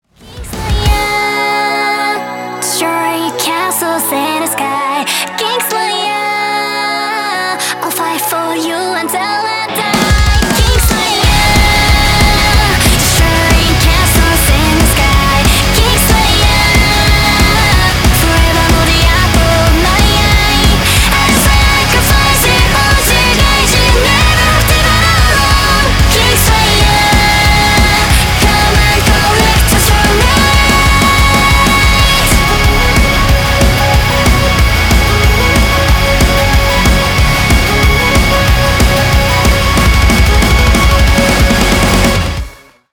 Рок рингтоны, Громкие рингтоны